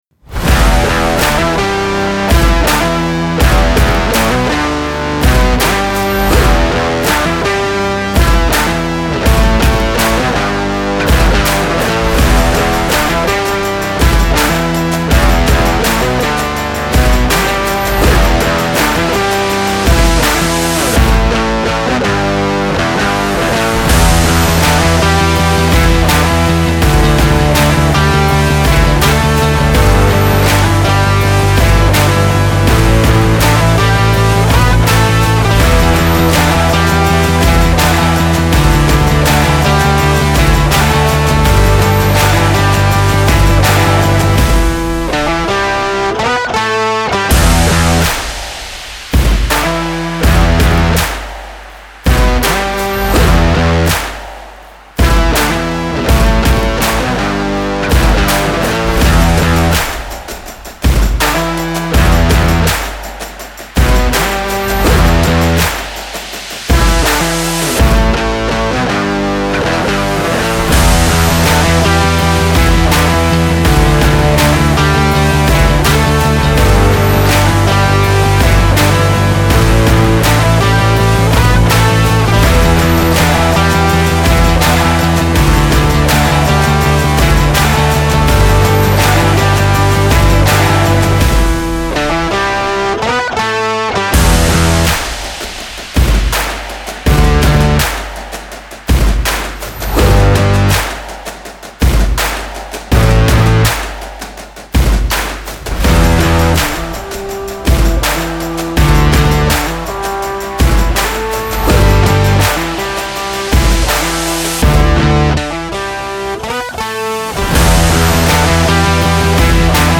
موسیقی کنار تو
امید‌بخش , پر‌انرژی , گیتار الکترونیک , موسیقی بی کلام